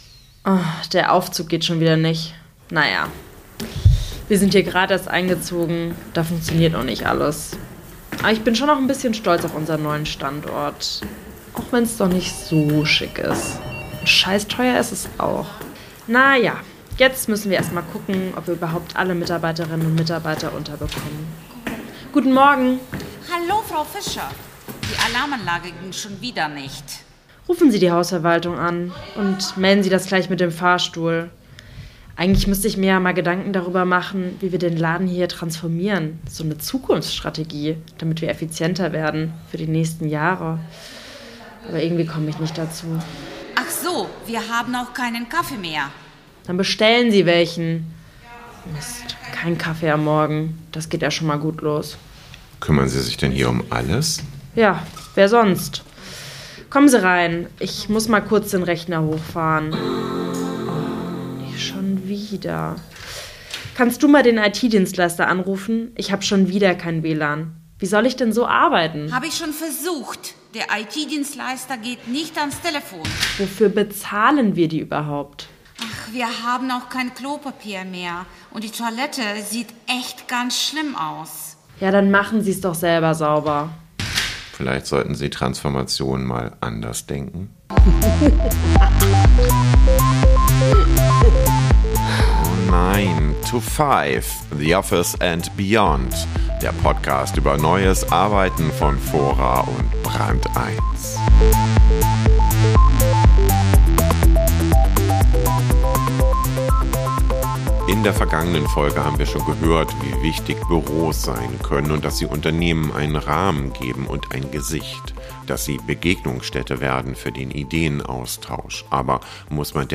Das Interview wurde im Fora-Pressehaus Podium in Berlin aufgezeichnet.